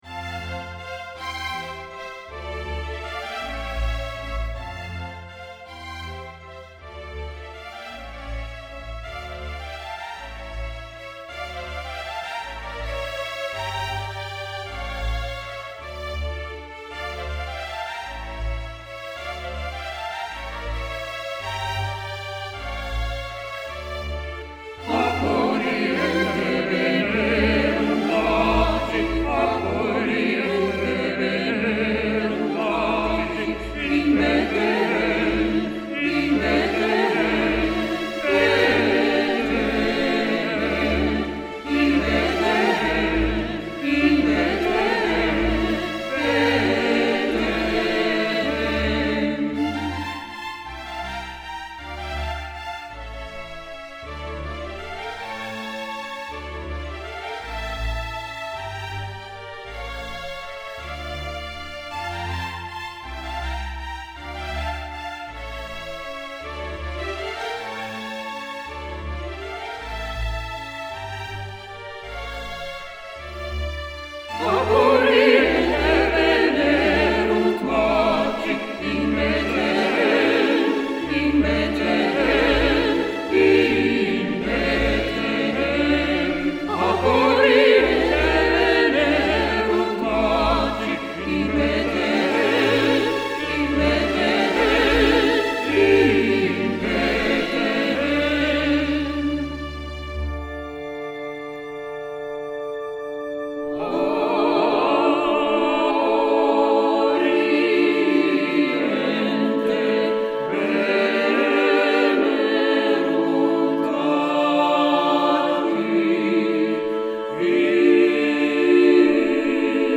3-osainen sinfoninen teos